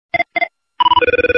Kategori: Nada dering
monophonic old default